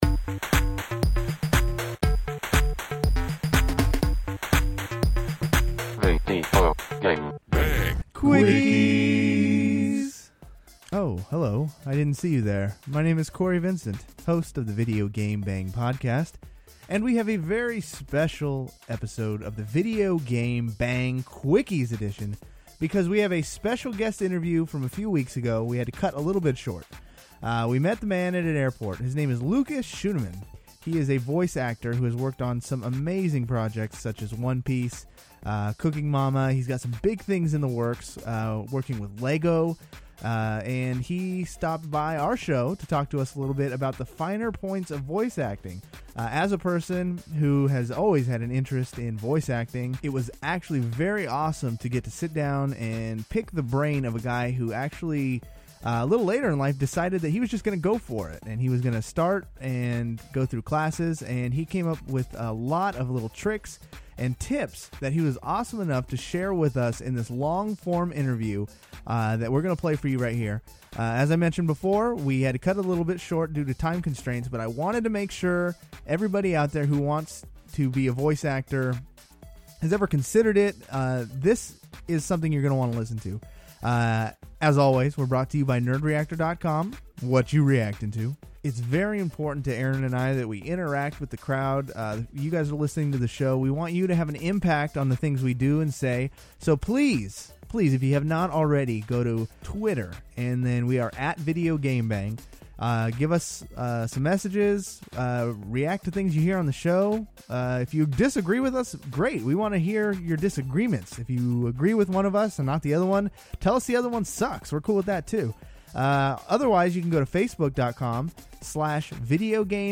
VGB Quickies: Full Interview